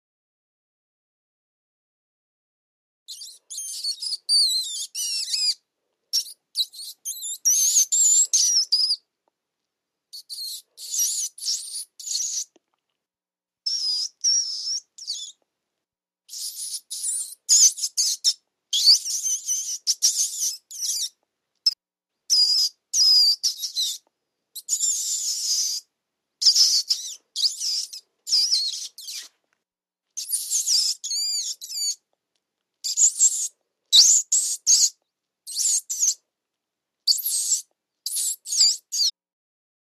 ANIMALS WILD: Single rat squeaking.